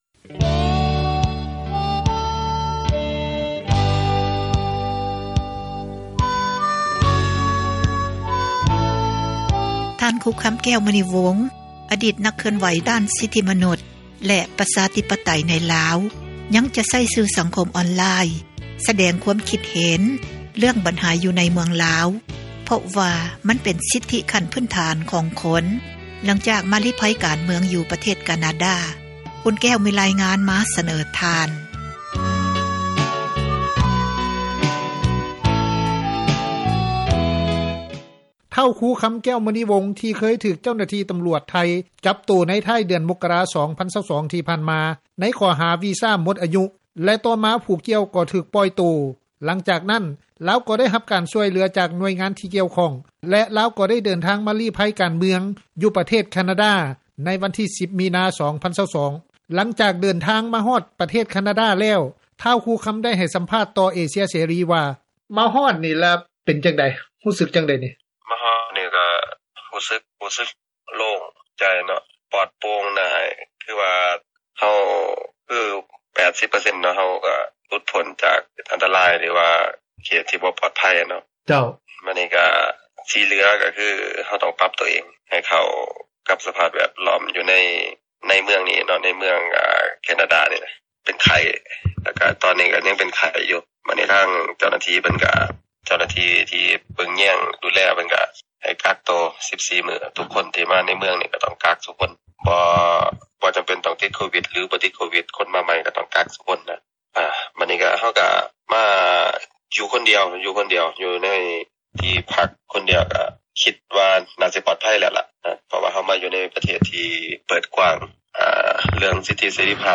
ໄດ້ໃຫ້ສຳພາດຕໍ່ເອເຊັຽເສຣີ ເສຣີ ວ່າ.